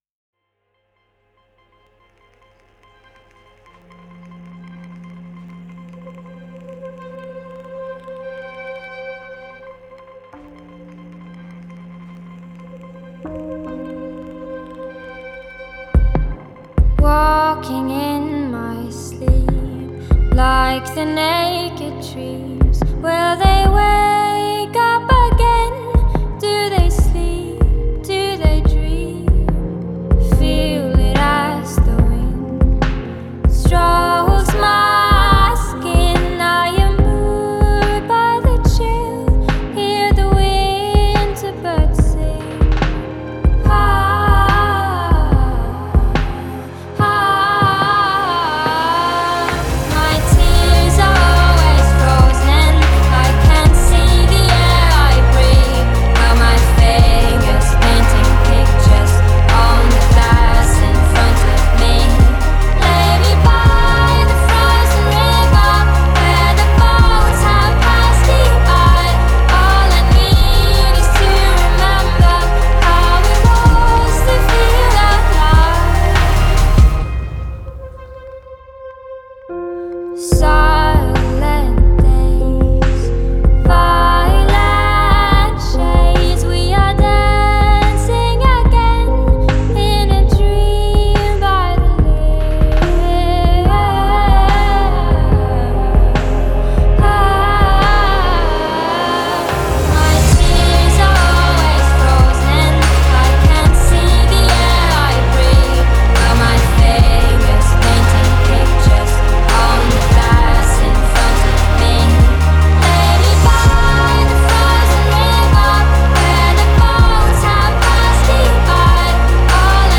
صدای بهشتی موزیک خارجی